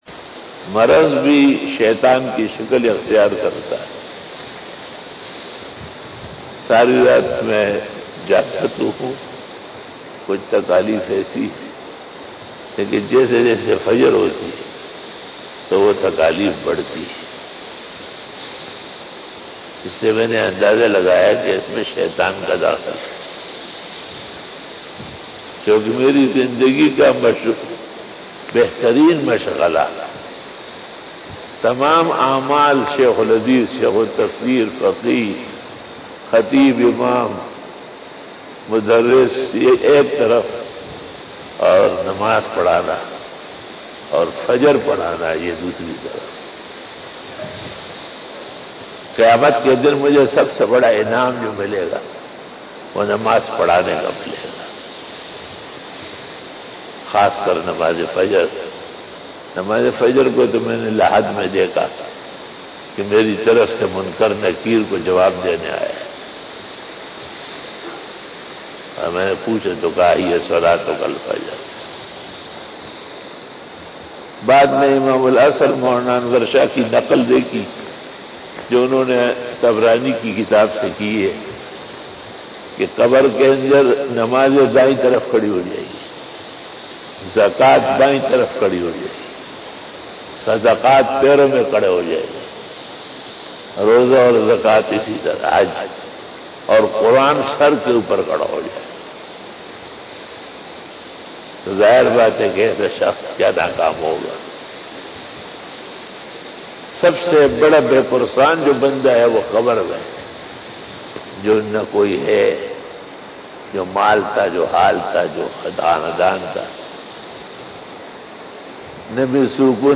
11:46 AM 488 After Namaz Bayan 2020 --